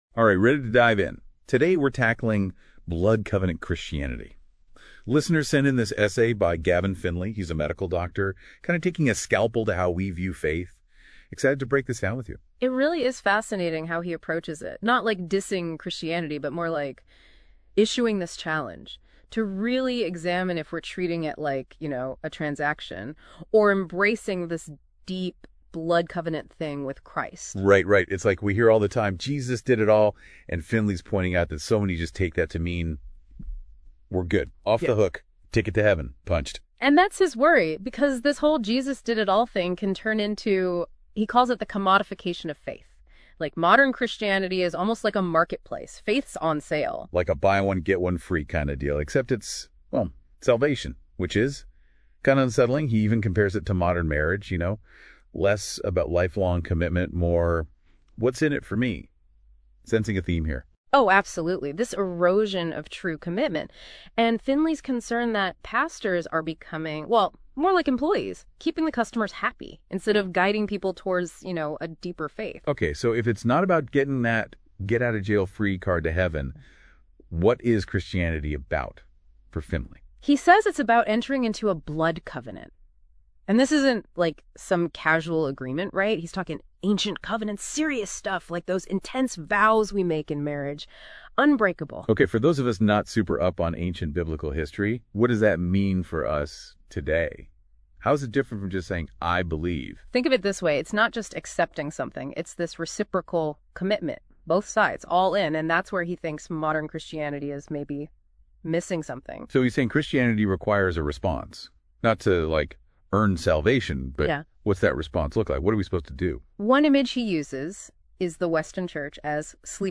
Here is an LM Notebook Dialogue for this article.